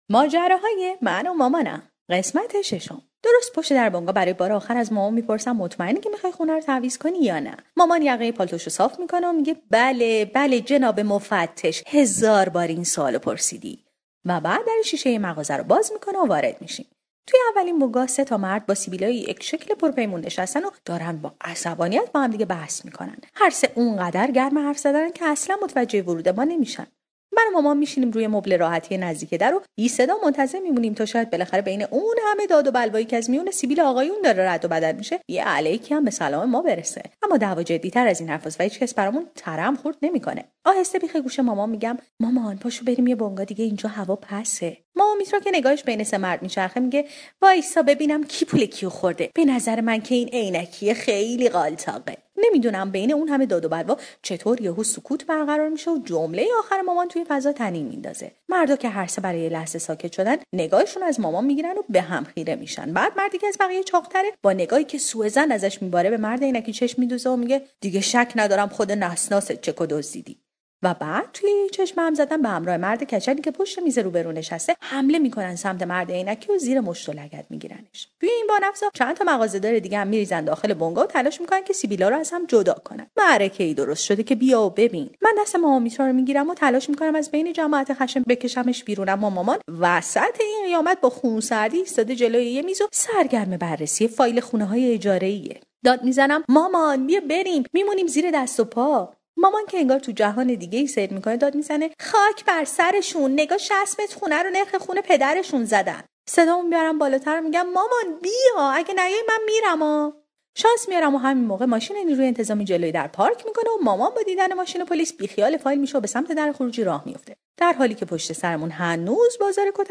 طنز صوتی / ماجراهای من و مامانم 6